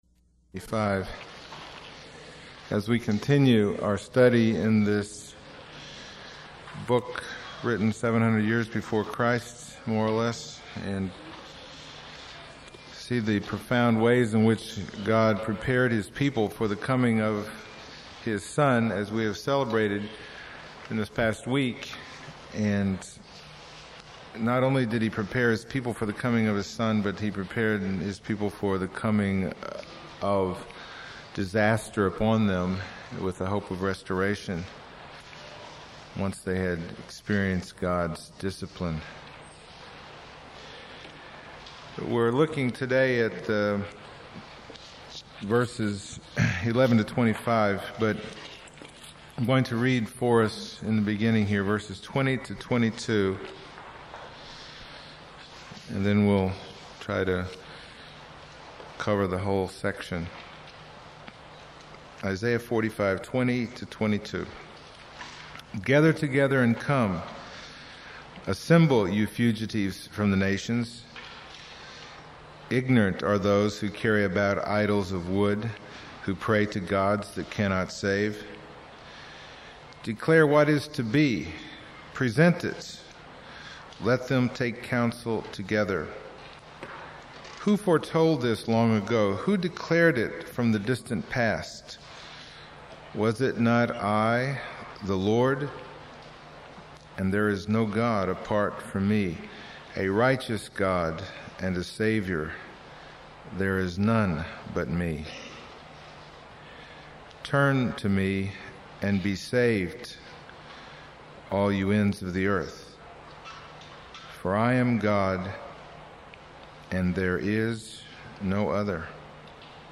Passage: Isaiah 45:11-25 Service Type: Sunday Morning